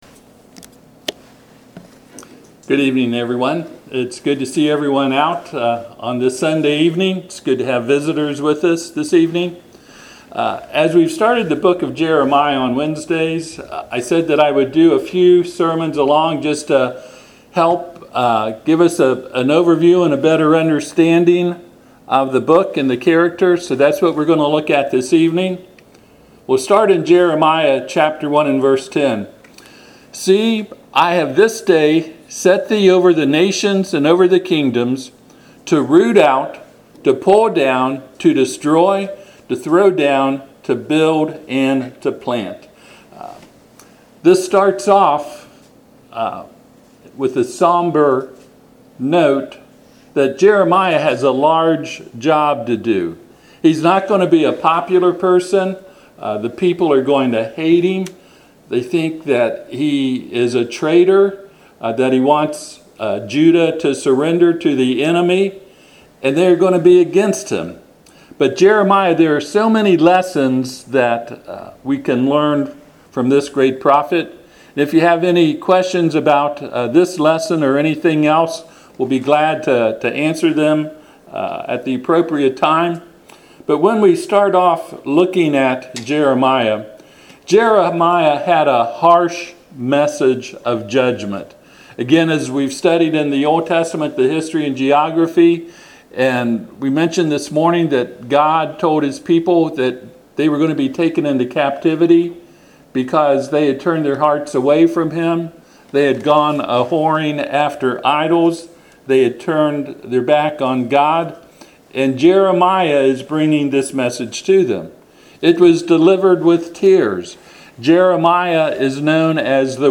Service Type: Sunday PM